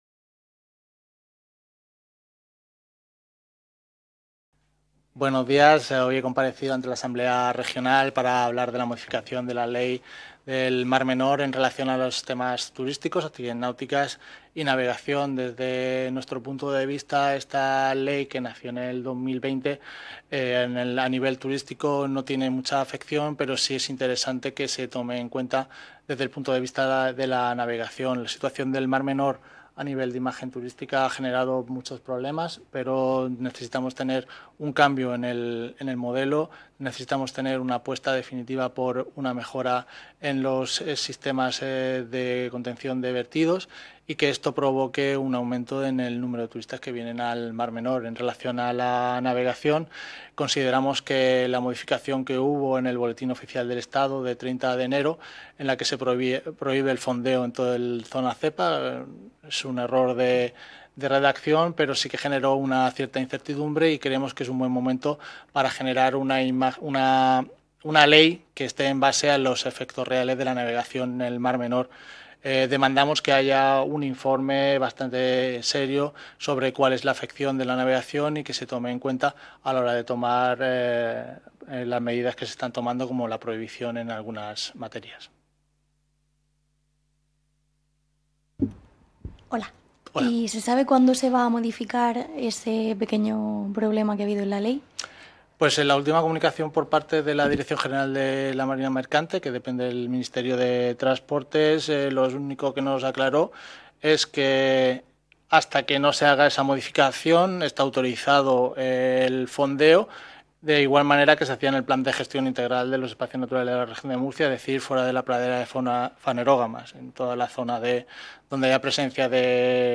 Rueda de prensa posterior a la Comisión de Asuntos Generales e Institucionales, de la Unión Europea y Derechos Humanos | Asamblea Regional de Murcia